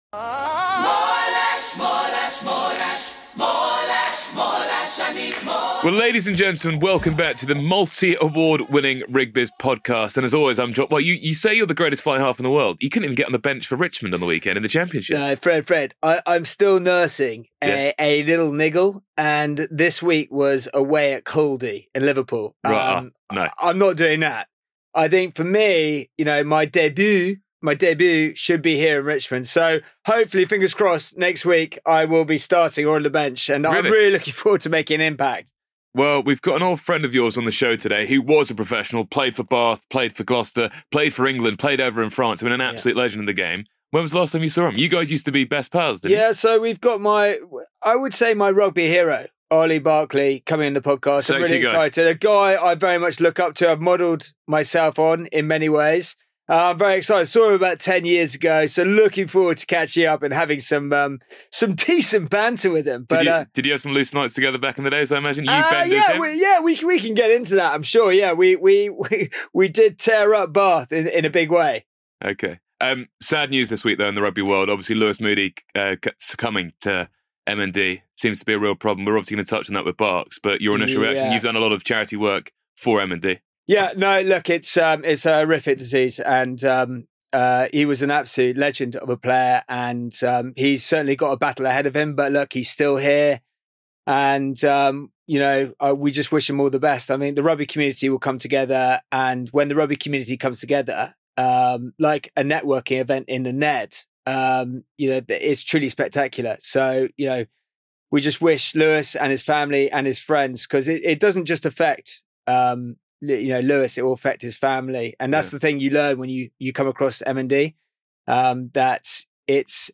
England rugby legend Olly Barkley joins The Rig Biz Podcast this week for a no-holds-barred conversation packed with nostalgia, chaos and heart.